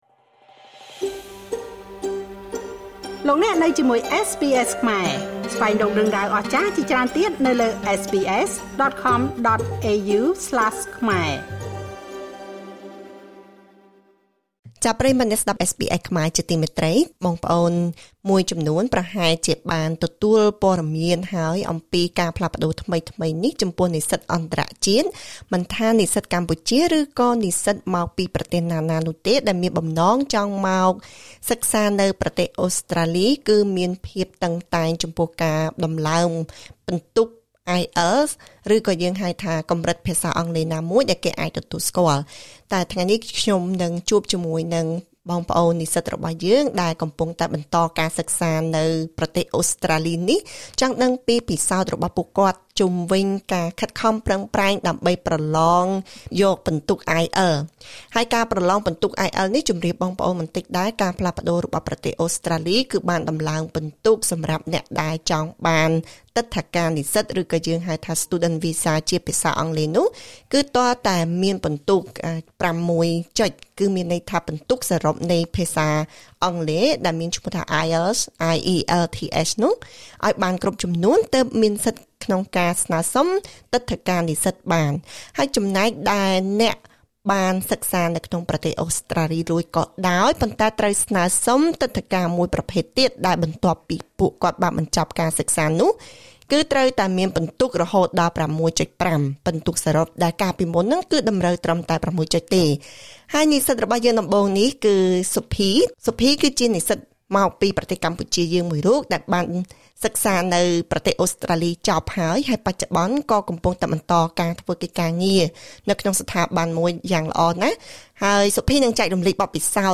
ការផ្លាស់ប្តូរនេះទាមទារឲ្យនិស្សិតអន្តរជាតិទាំងអស់ ត្រូវតែត្រៀមលក្ខណៈឲ្យបានល្អក្នុងការប្រឡង ទើបអាចទទួលបានពិន្ទុគ្រប់តាមតម្រូវការរបស់រដ្ឋាភិបាល។ សូមស្តាប់បទសម្ភាសជាមួយនិស្សិតខ្មែរពីររូបដែលកំពុងបន្តការសិក្សានៅប្រទេសអូស្រ្តាលី។